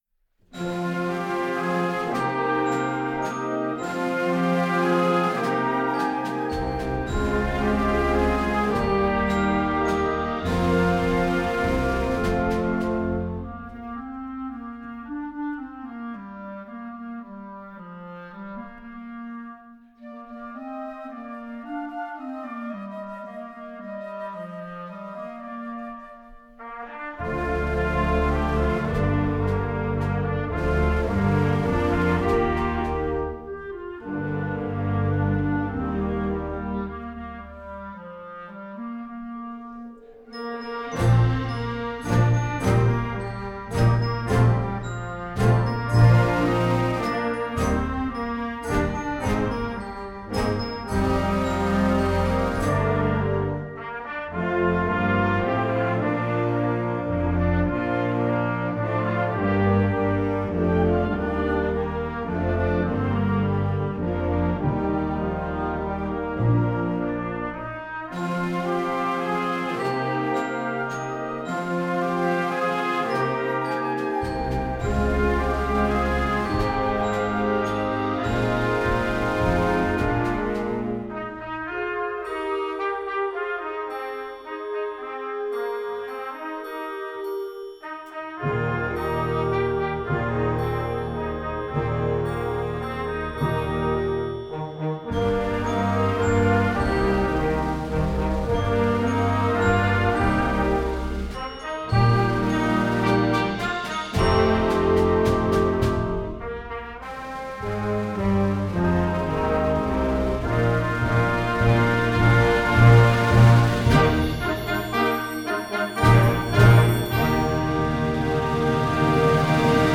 Gattung: für Jugendblasorchester
Besetzung: Blasorchester
Die volkstümliche pentatonische Melodie